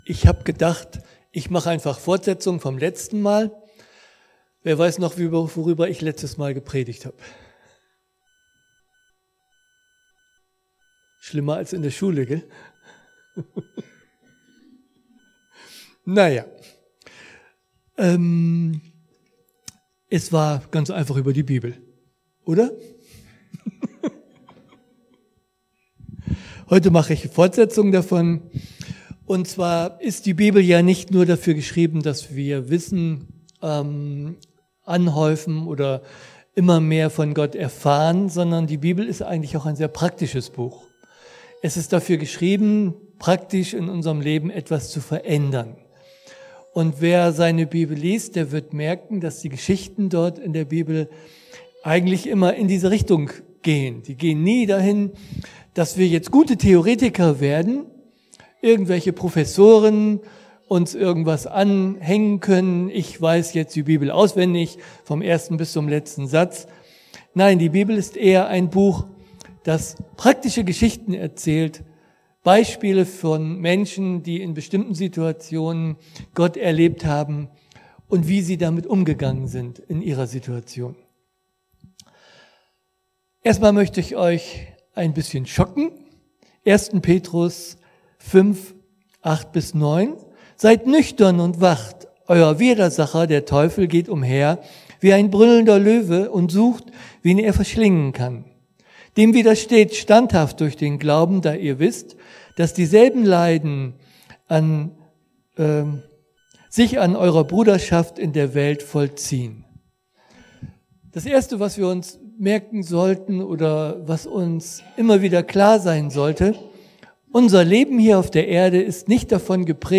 Petrus 5, 8-9 Dienstart: Predigt Bible Text: 1.